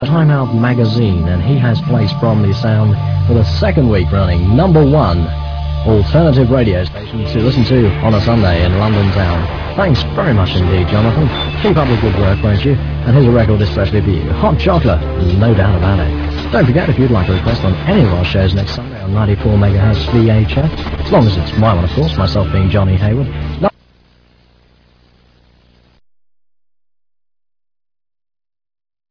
Here you can listen to off air and studio recordings of landbased pirate radio stations, they feature stations based in London and the home counties from the late 70's to almost the present day